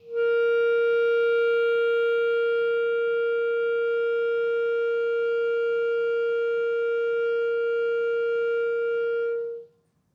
Clarinet
DCClar_susLong_A#3_v2_rr1_sum.wav